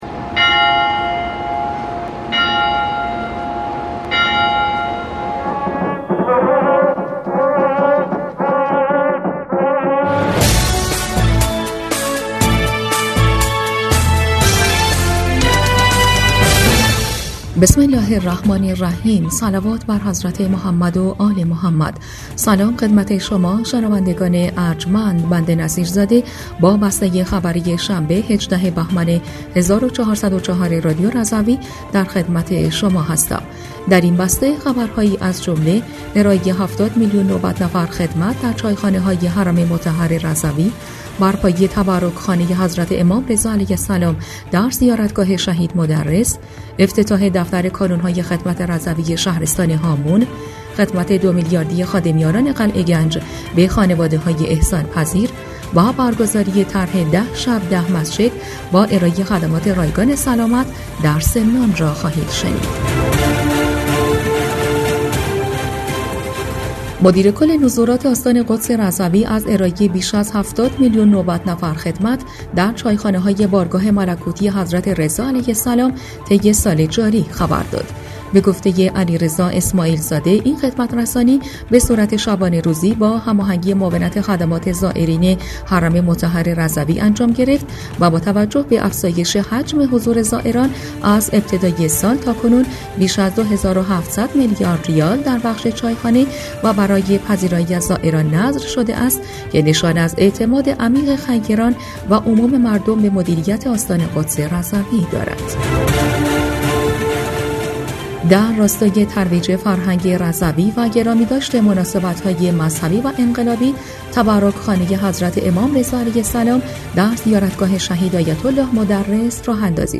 بسته خبری ۱۸ بهمن ۱۴۰۴ رادیو رضوی؛